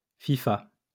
3. ^ French pronunciation: [fifa]